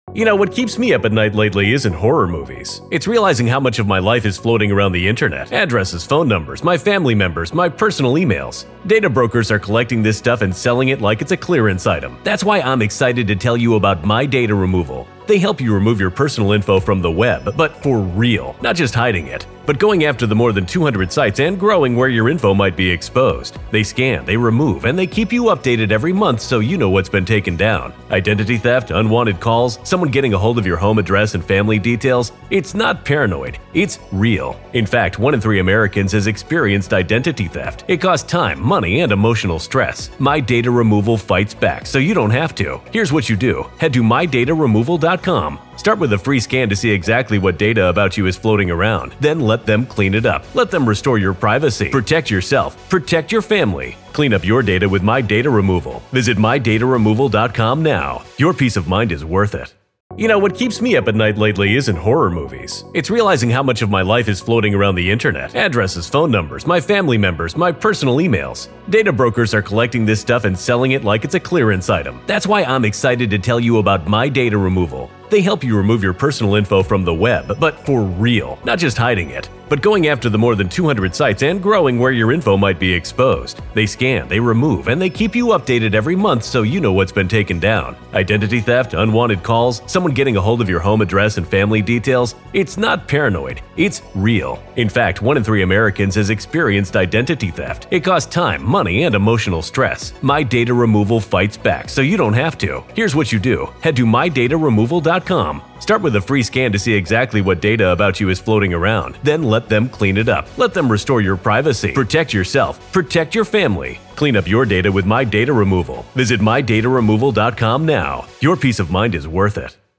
Hidden Killers Live closes out its Daybell coverage with a mix of dark humor and jaw-dropping audio. First, the hosts riff on Chad Daybell’s letters as if they were a “Top 40” radio countdown — imagining long-distance dedications from death row.
But the laughter fades when the show pivots to Lori Vallow’s Arizona courtroom monologue.